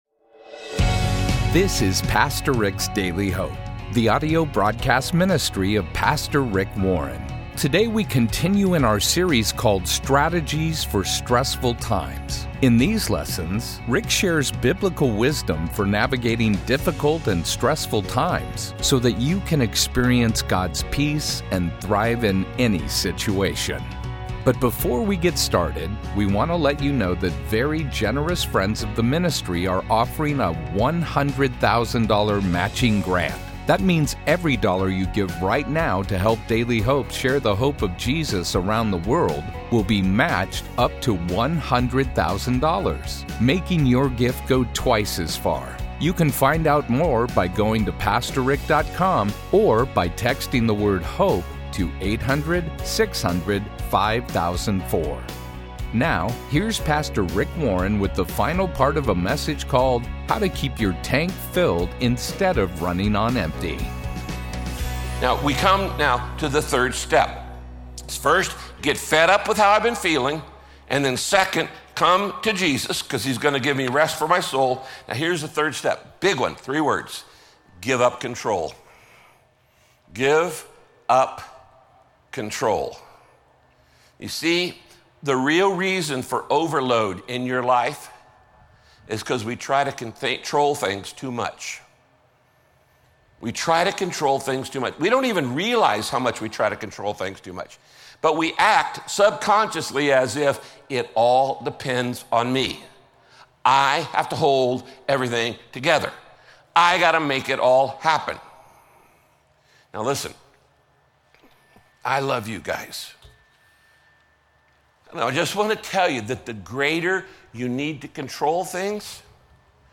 Listen to this broadcast by Pastor Rick to continue learning about the calm and rest only Jesus offers.
Radio Broadcast How To Keep Your Tank Filled Instead of Running on Empty – Part 3 The antidote to the two biggest causes of stress in your life is gentleness and humility.